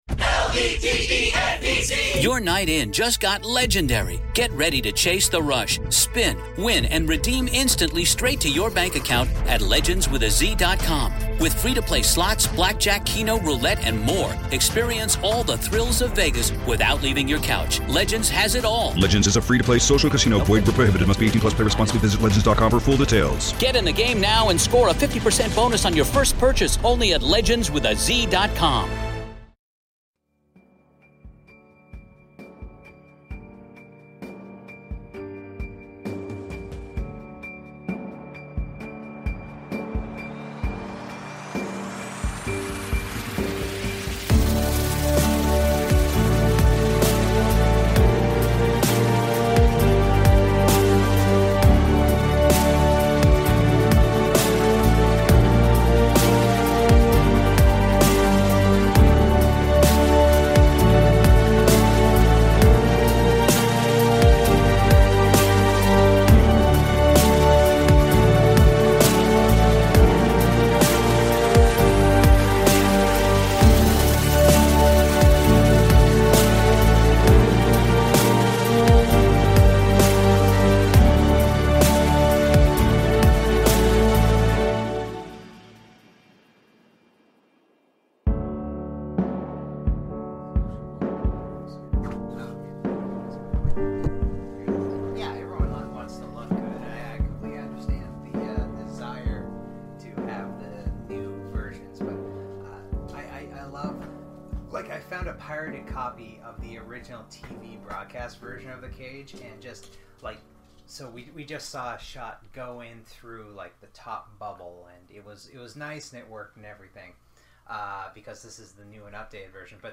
Recorded pre-pandemic